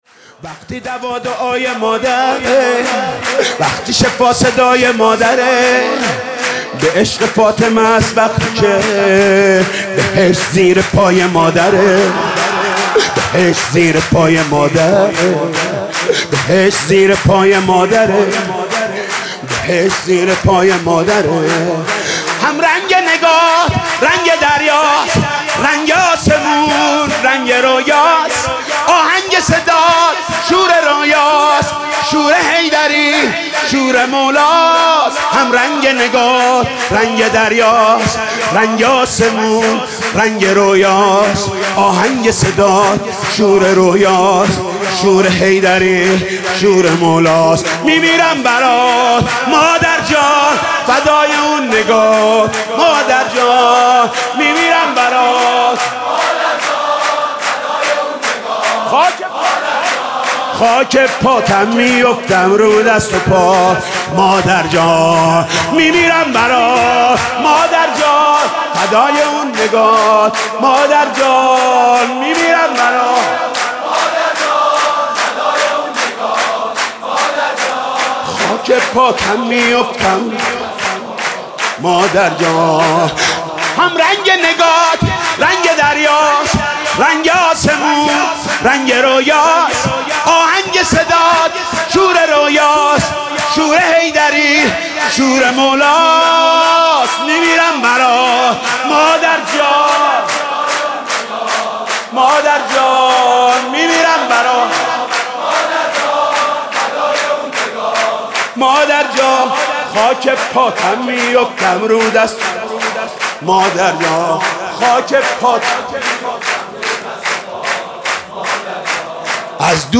سرود: وقتی دوا دعای مادر